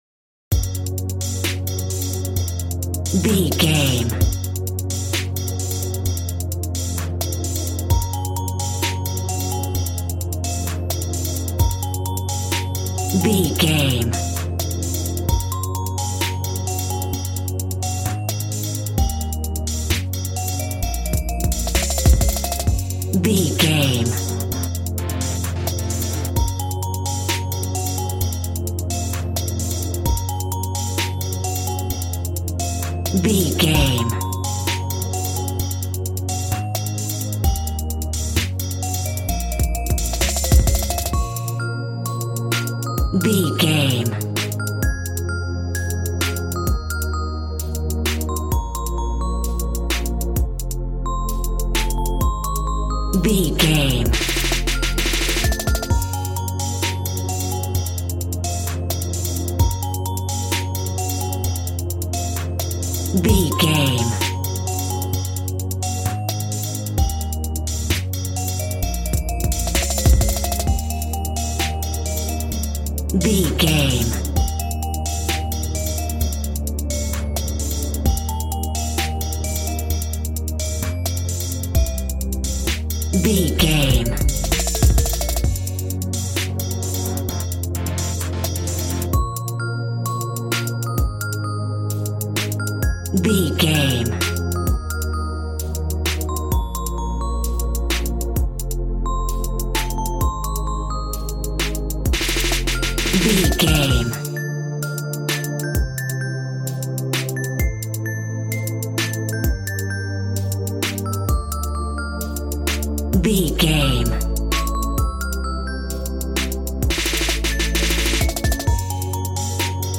Aeolian/Minor
B♭
Fast
groovy
synthesiser
drums
piano